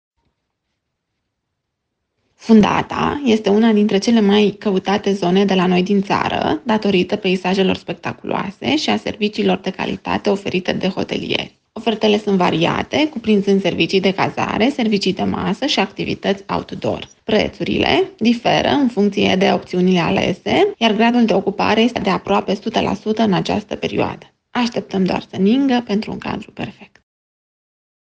Cabanier